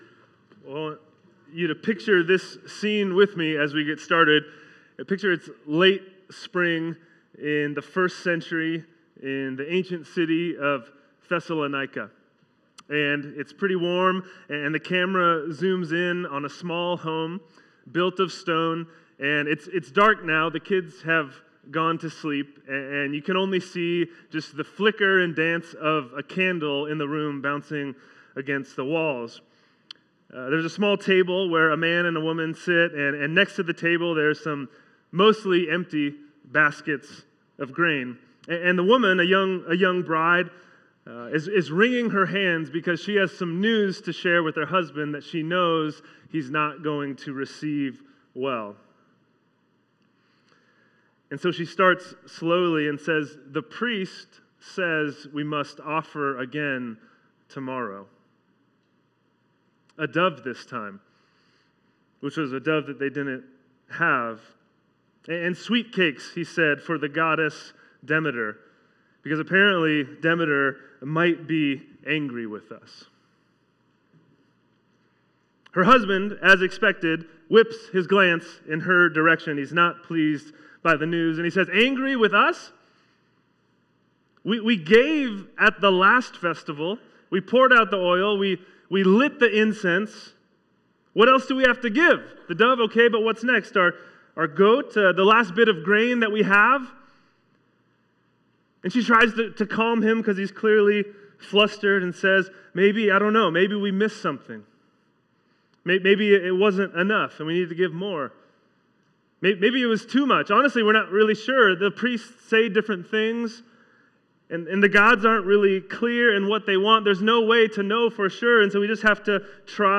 Sermons | First Baptist Church of Benicia